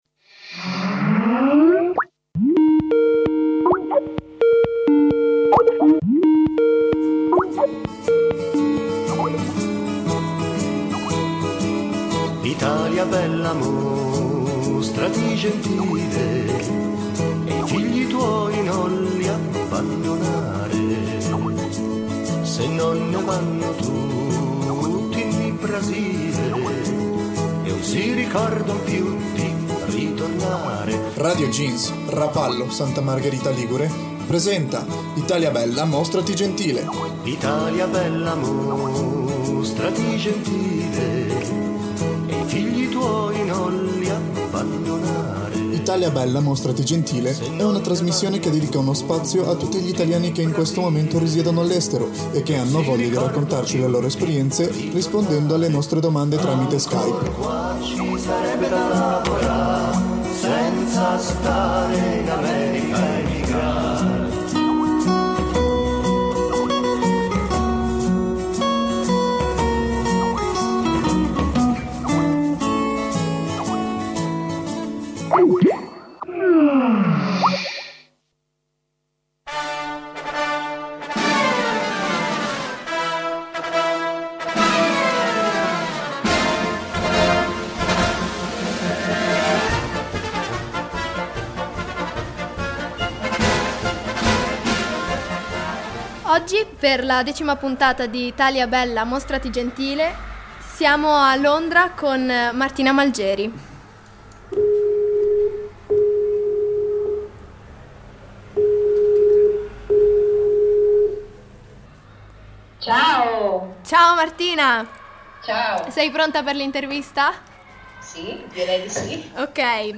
come ci spiega nell'intervista